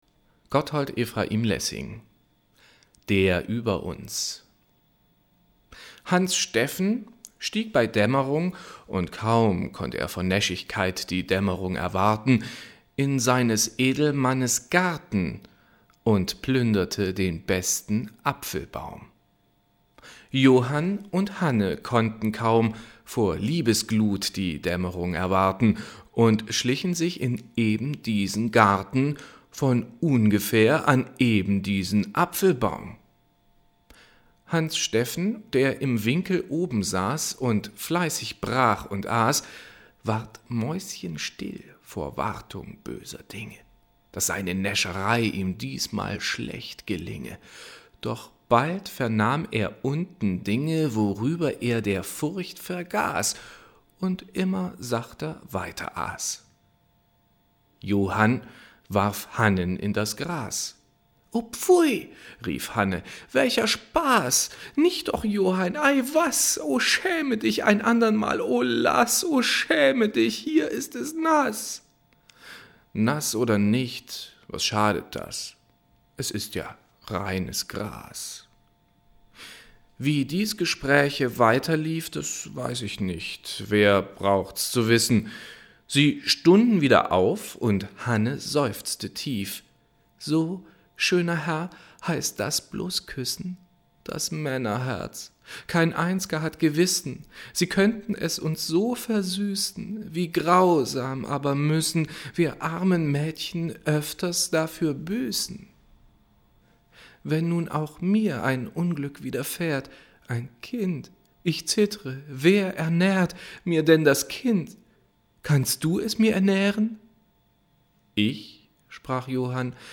LESUNGEN Lessing: Der über uns